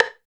39 STICK  -L.wav